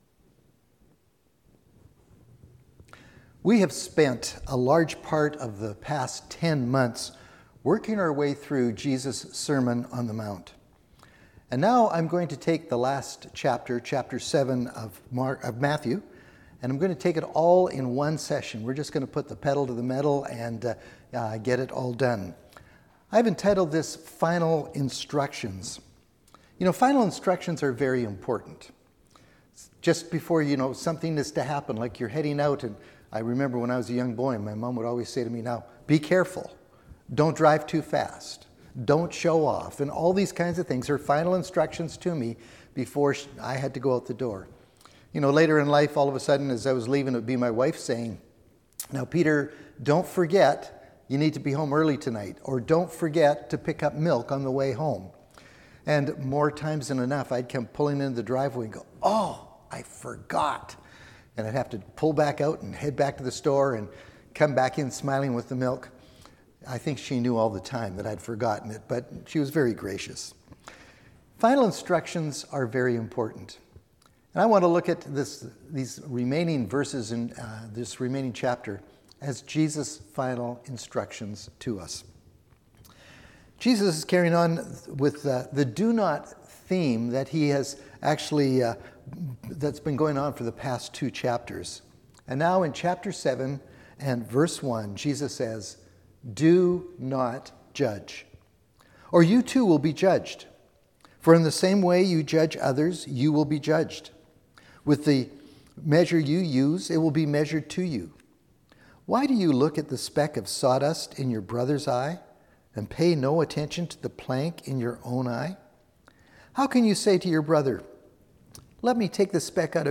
"Final Instructions"... the final sermon in what's been an amazing series on Jesus' Sermon On The Mount.